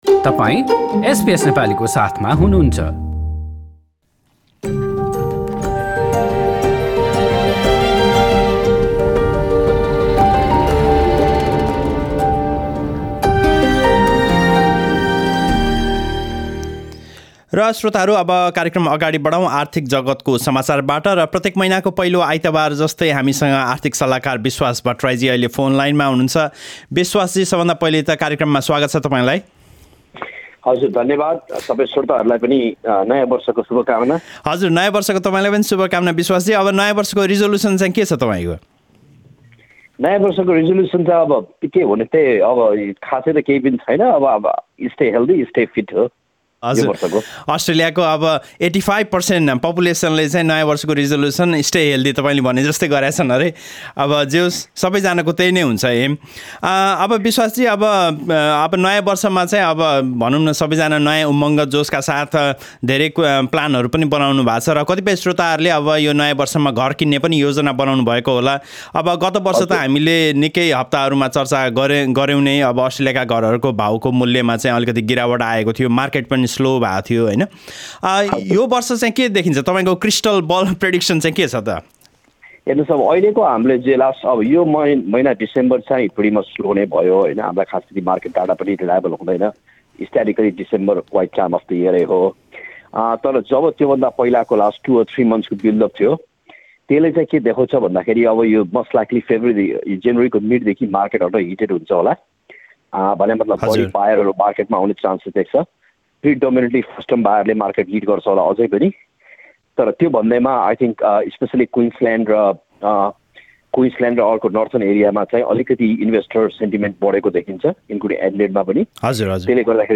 यस विषयमा हामीसँग गर्नुभएको कुराकानी।